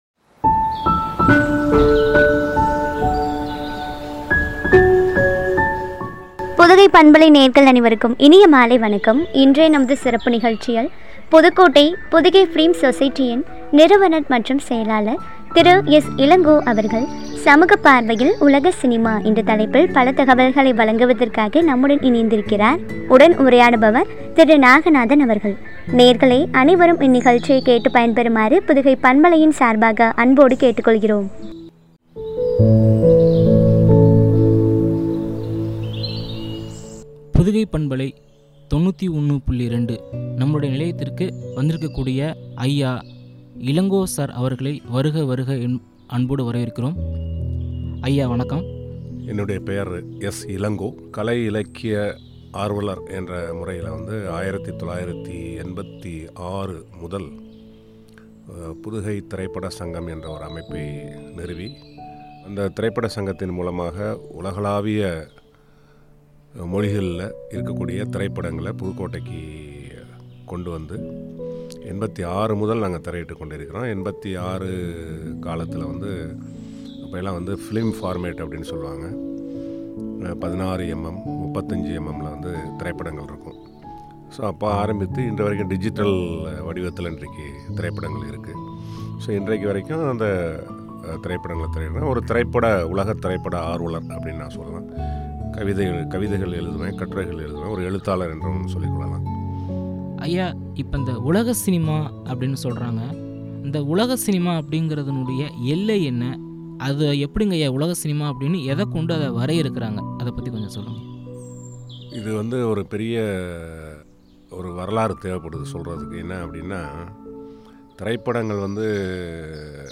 சமூக பார்வையில் உலக சினிமா பற்றிய உரையாடல்